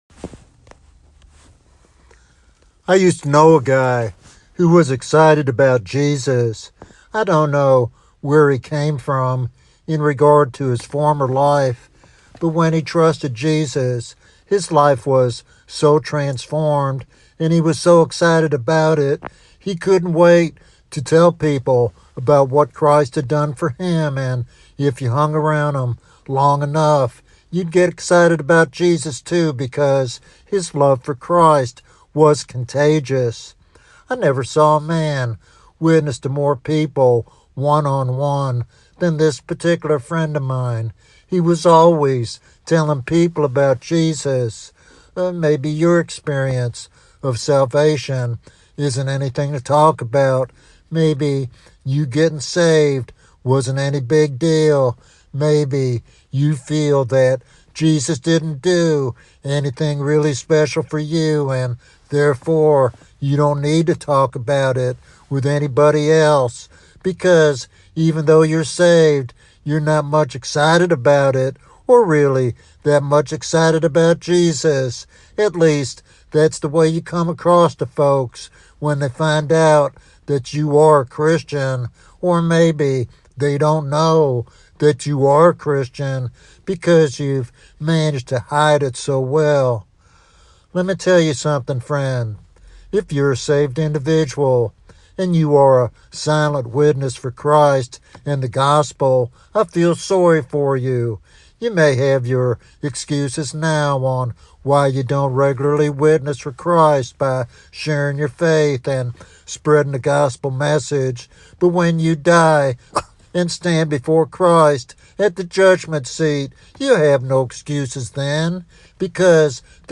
In this heartfelt sermon